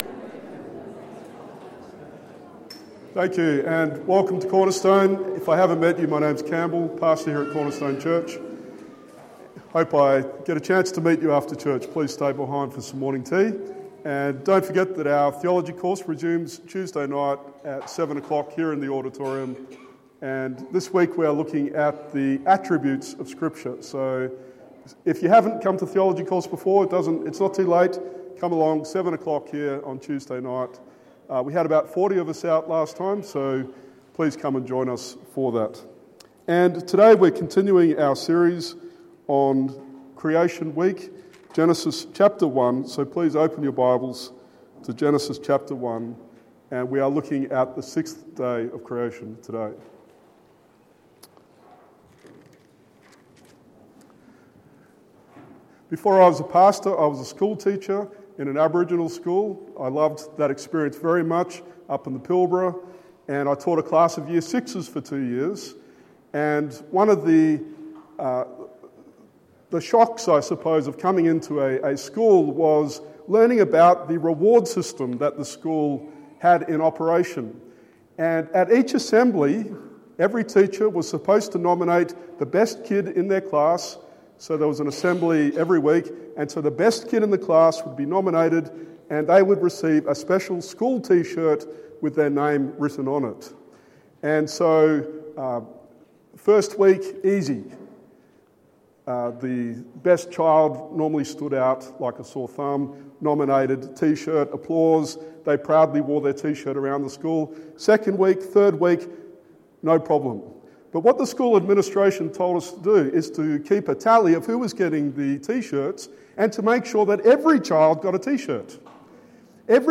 Genesis 1:24-31 Sermon